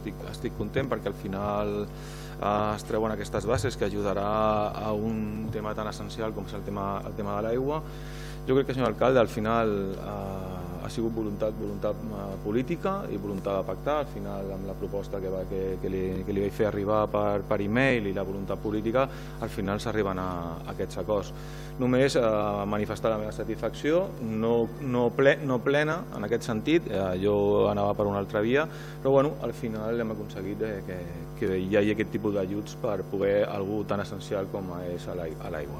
El regidor no adscrit, Albert Sales, també va destacar la voluntat política que havia permès arribar a aquest acord: